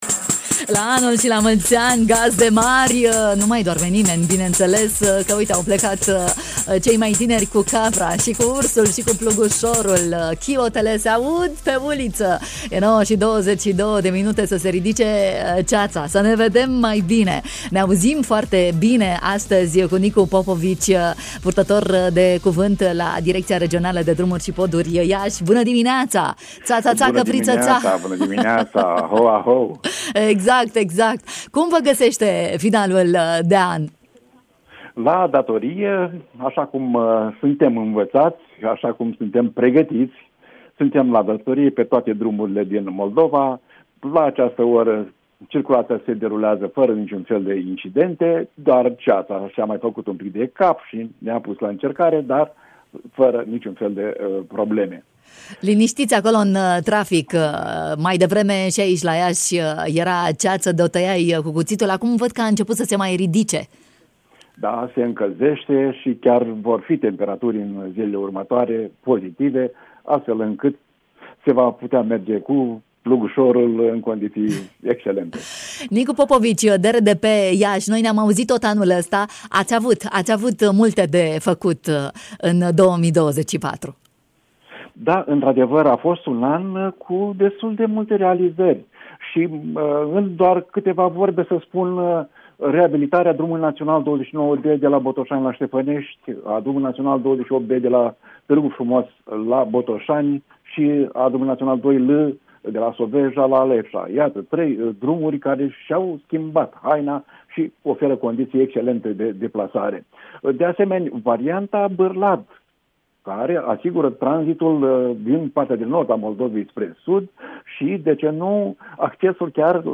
în direct, în ajun de an nou